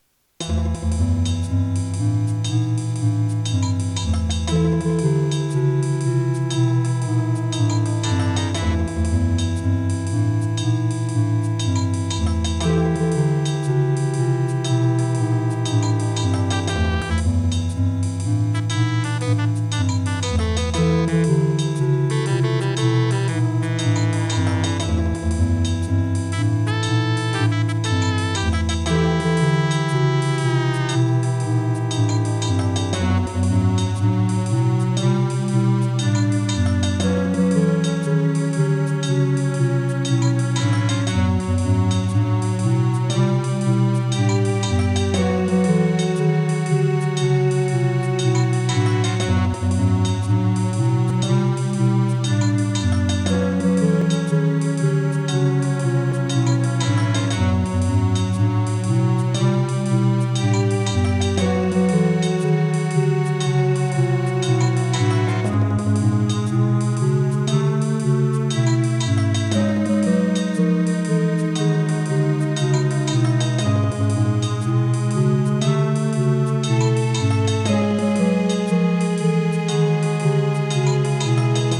It is an unused music track.